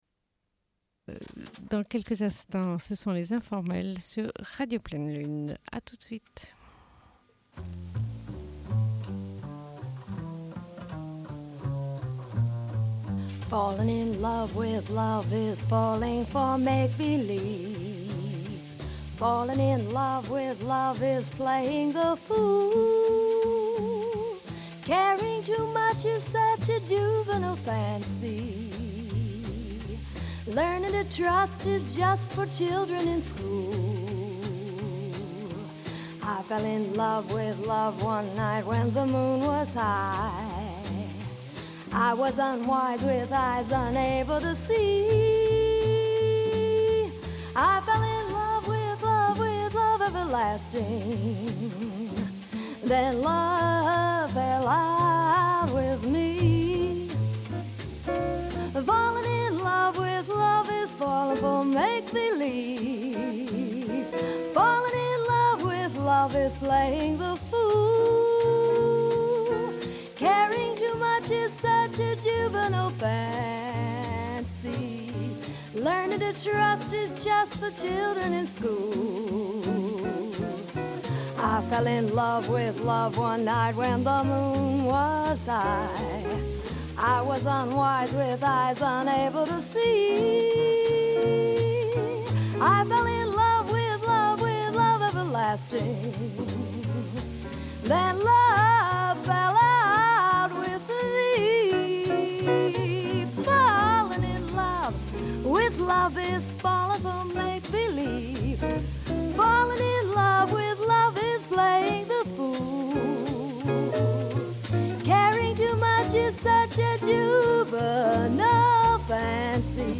Une cassette audio, face B31:17